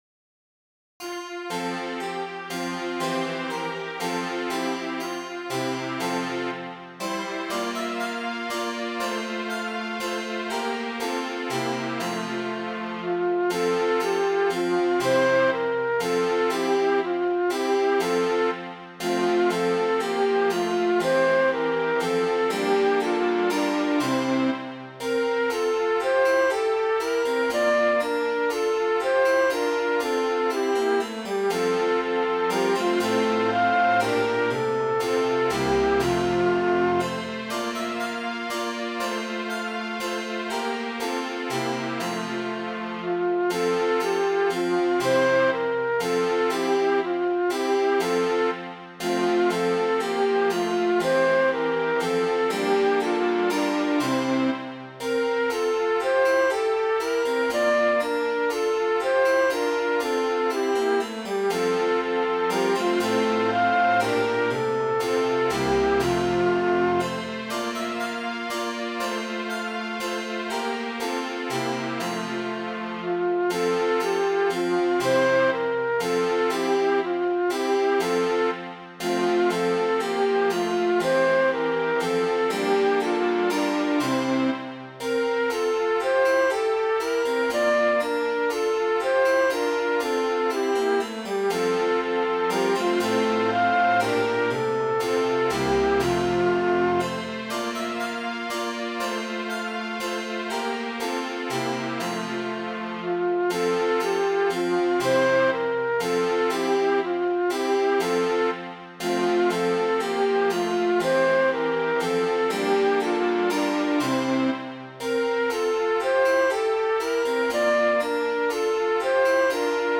Midi File, Lyrics and Information to The Rich Nobleman and His Daughter